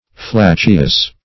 Flatuous \Flat"u*ous\, a.
flatuous.mp3